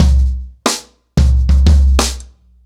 Wireless-90BPM.35.wav